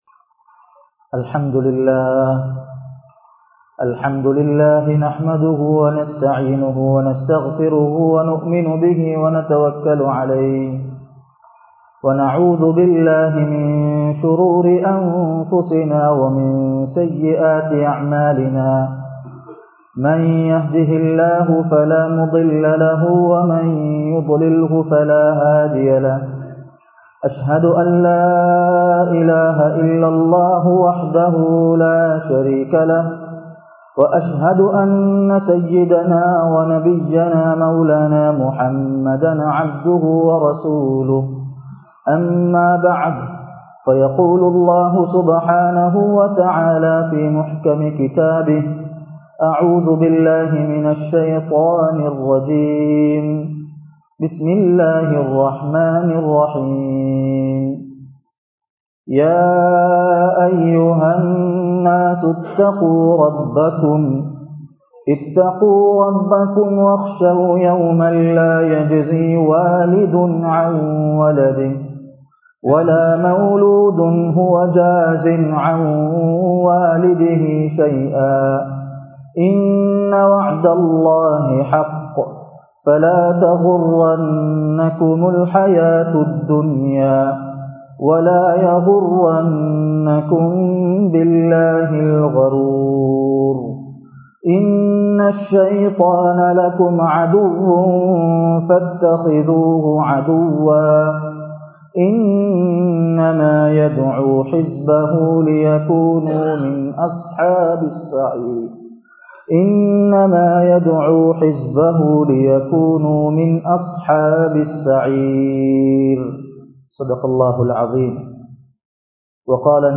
உயிரோட்டமான வீடுகள் | Audio Bayans | All Ceylon Muslim Youth Community | Addalaichenai
Alakoladeniya Jumma Masjidh